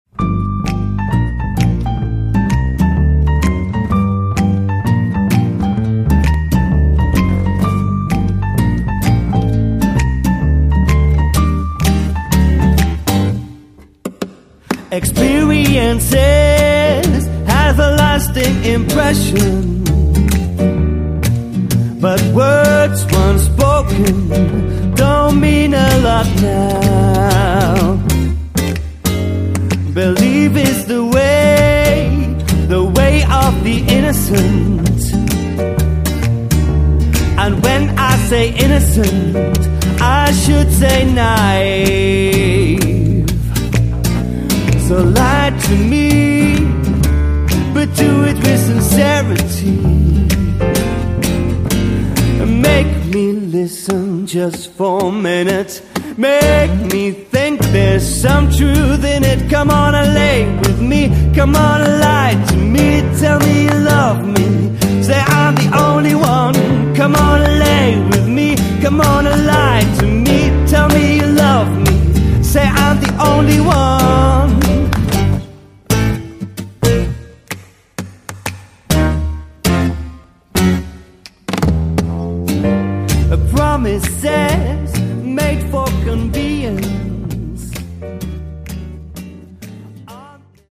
Live-Repertoire